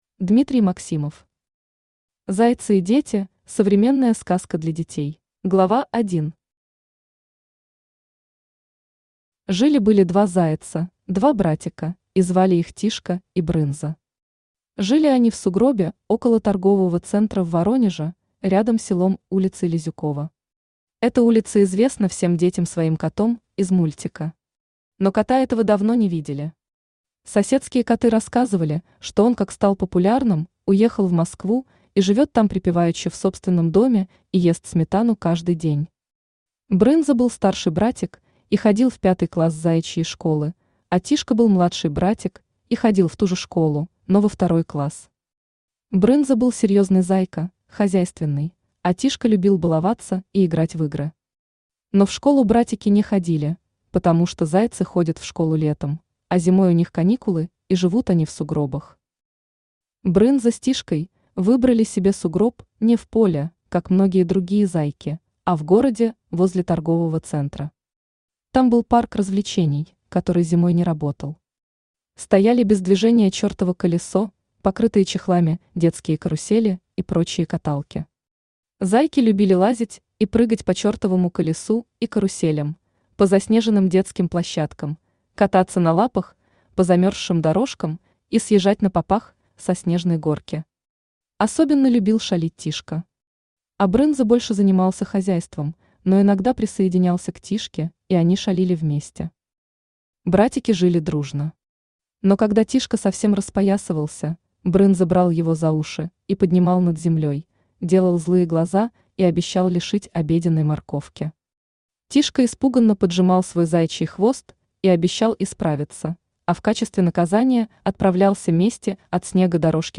Аудиокнига Зайцы и Дети, современная сказка для детей | Библиотека аудиокниг
Aудиокнига Зайцы и Дети, современная сказка для детей Автор Дмитрий Максимов Читает аудиокнигу Авточтец ЛитРес.